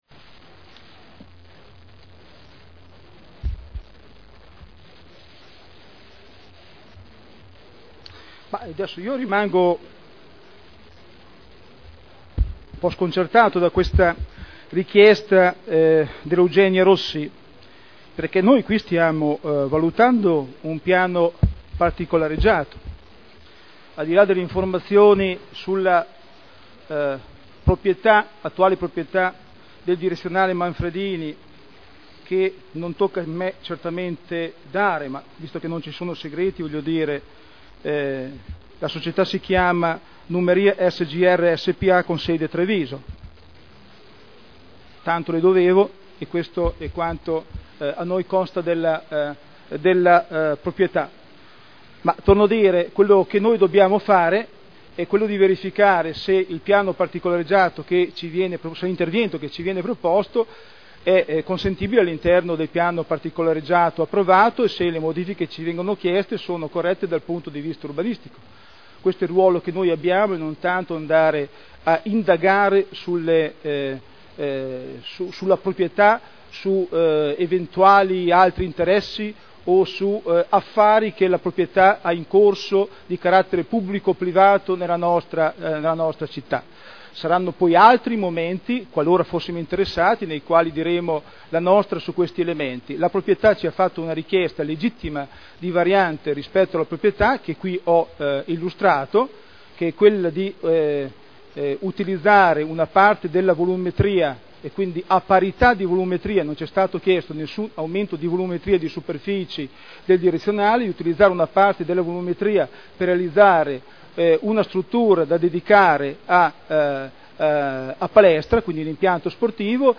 Seduta del 18/01/2010.